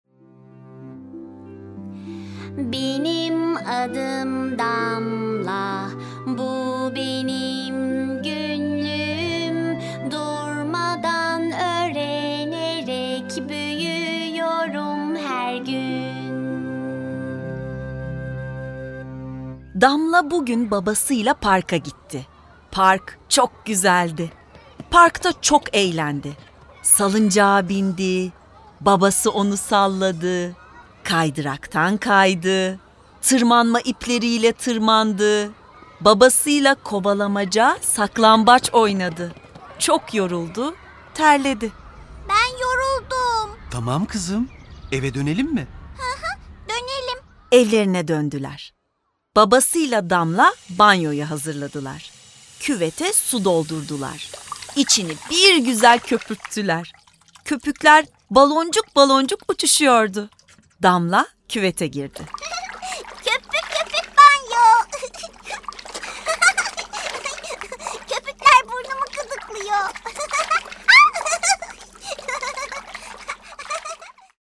Damla Banyo Yapıyor Tiyatrosu
Bu Sesli Tiyatro eseri, okumalı boyama kitaplarıyla desteklenmektedir.